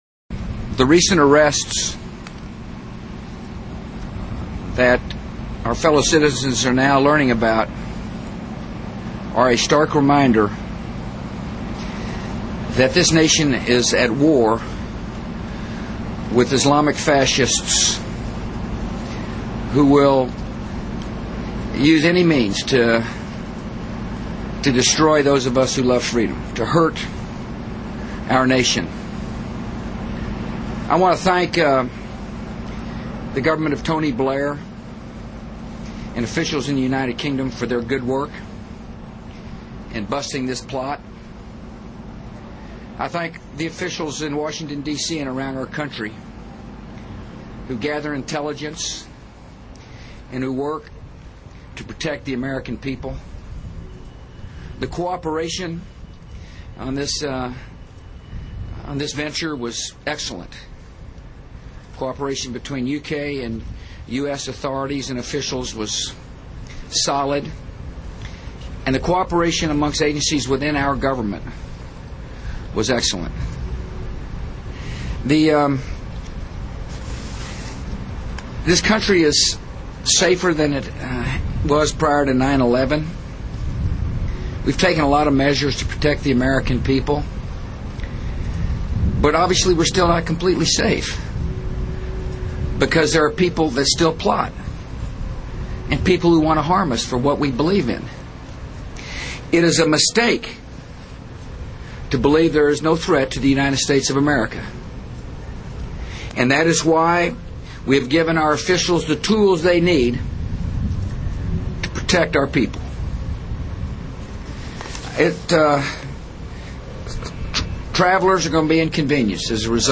Der Präsident der USA hielt heute in einer Rede zu den vereitelten Attentaten in England fest, dass "this nation is at war with islamic fascists".